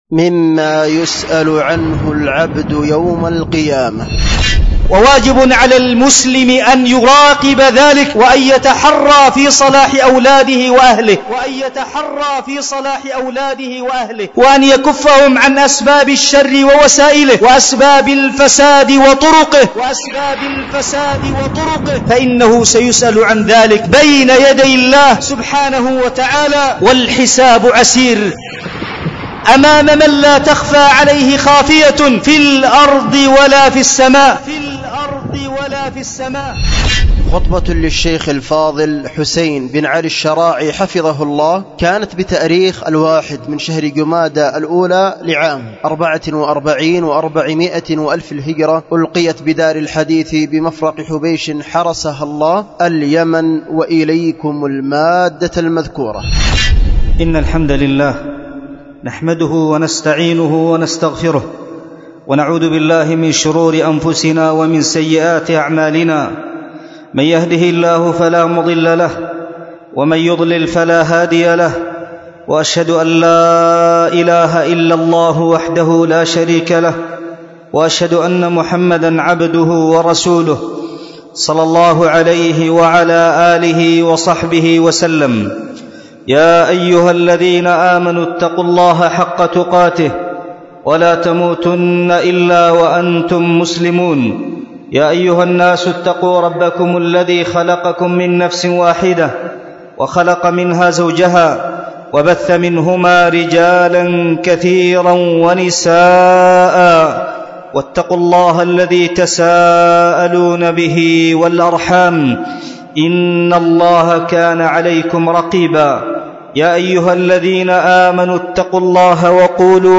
خطبة
بدار الحديث بمفرق حبيش